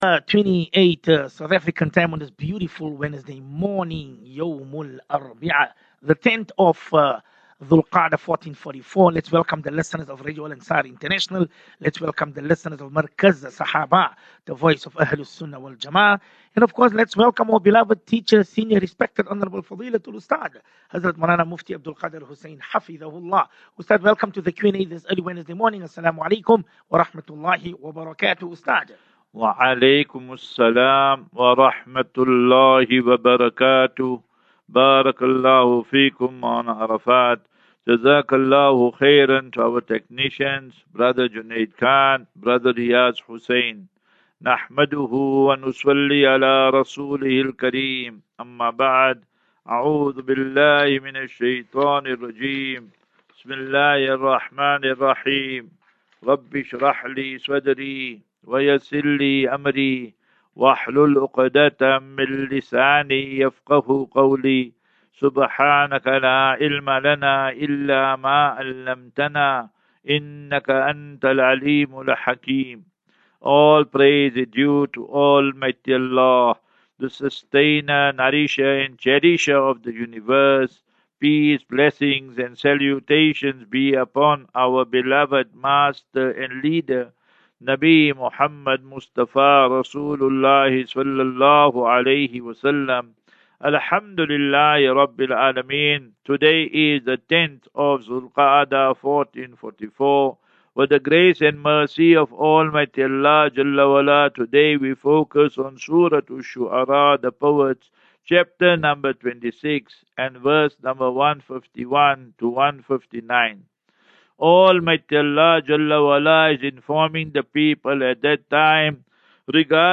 As Safinatu Ilal Jannah Naseeha and Q and A 31 May 31 May 23 Assafinatu